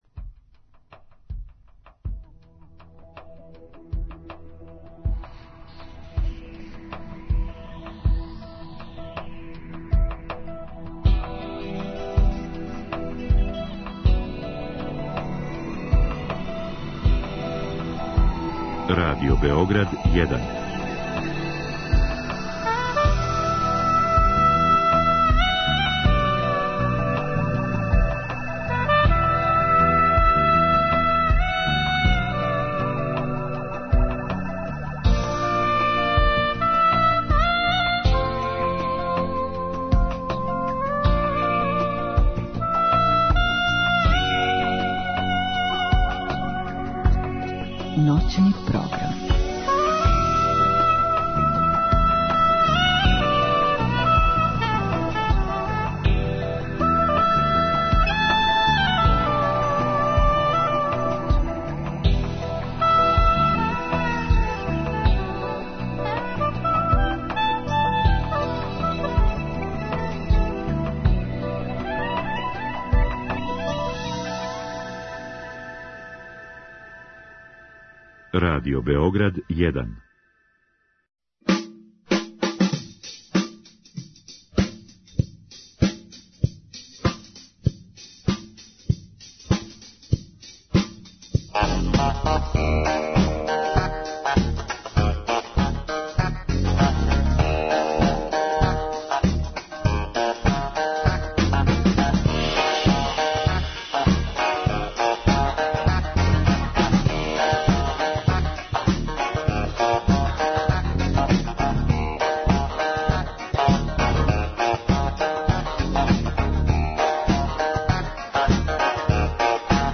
Како модерни мушкарци гледају на паметне жене? Музика уживо и још много тога.